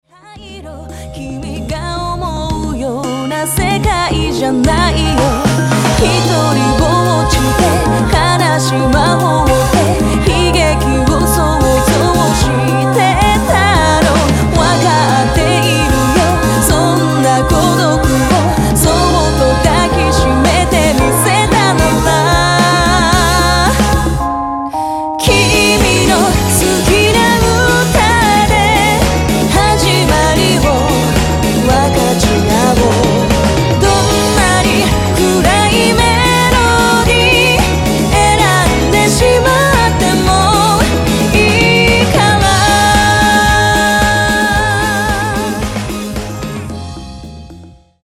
クロスフェードデモ